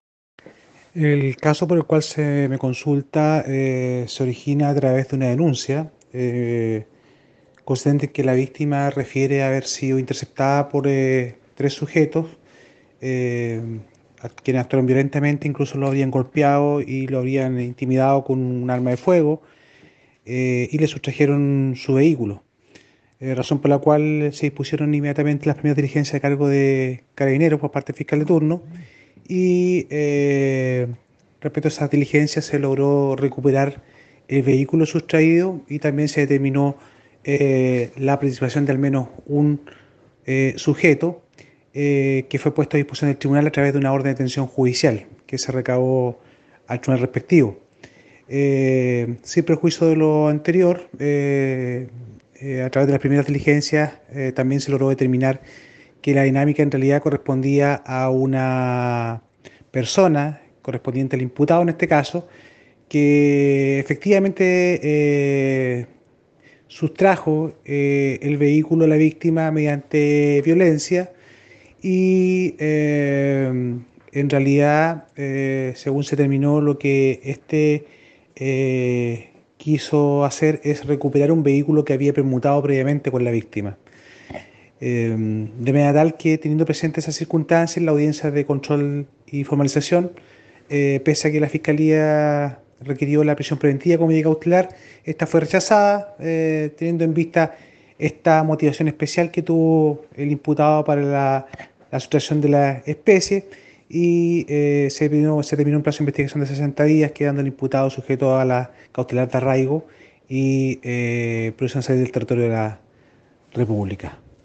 Fiscal ……Sergio Fuentes sobre la formalización realizada por la Fiscalía de Río Bueno .